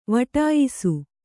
♪ vaṭāyisu